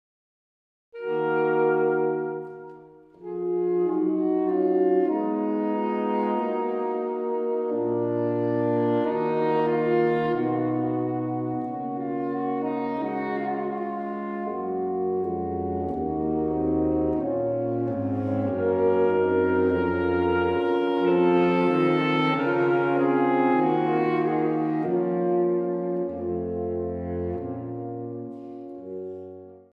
Grave 1:29